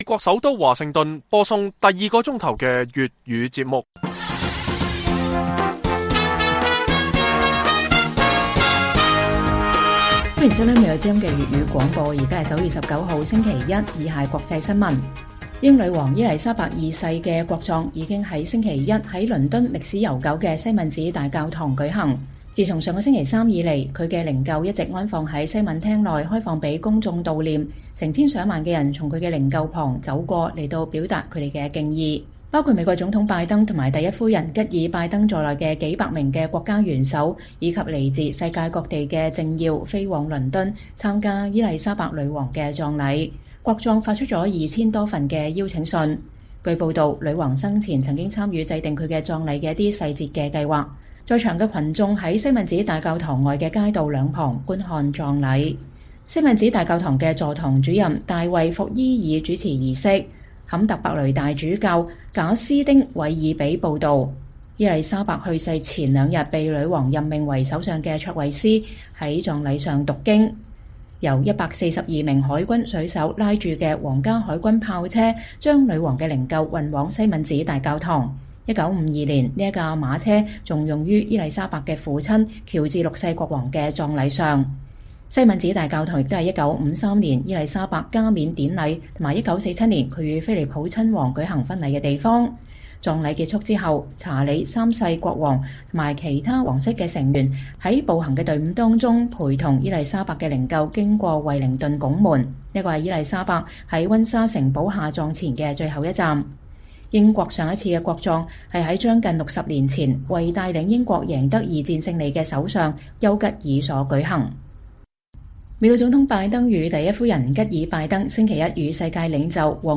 粵語新聞 晚上10-11點: 英女王國葬週一在倫敦西敏寺大教堂舉行